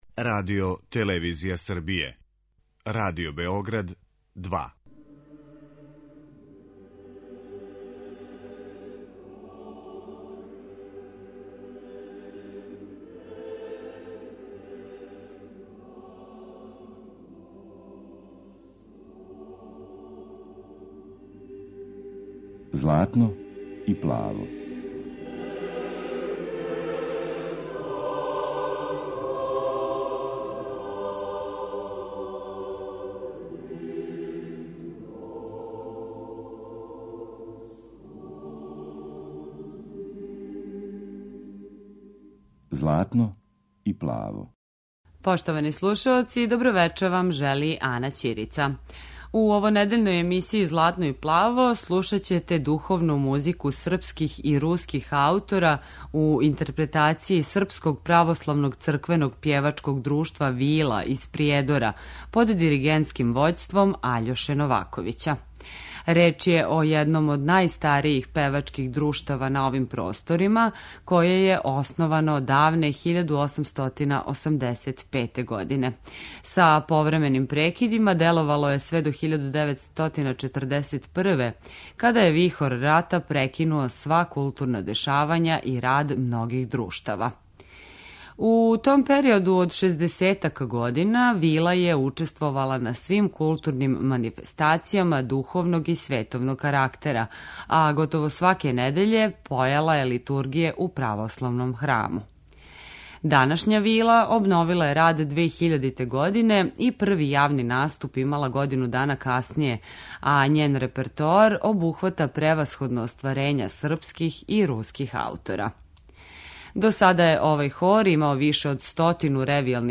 Слушаћете духовну музику српских и руских аутора
Емисија посвећена православној духовној музици.